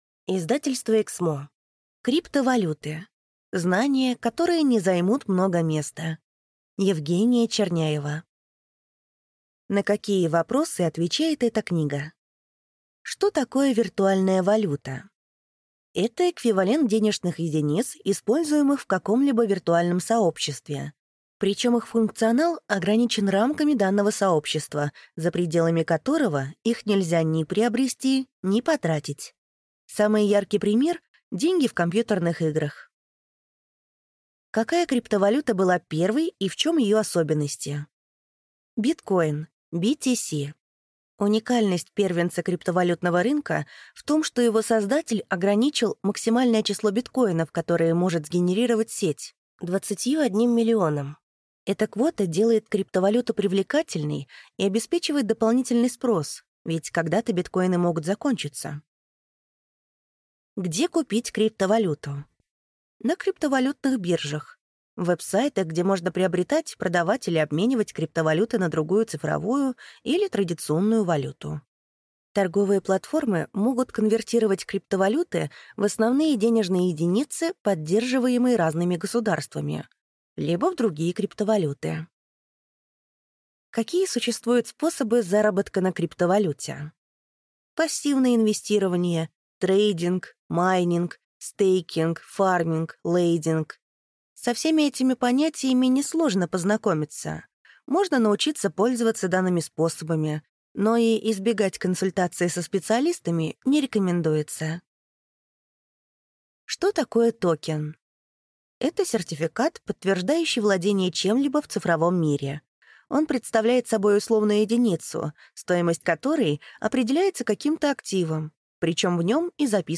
Аудиокнига Криптовалюты. Знания, которые не займут много места | Библиотека аудиокниг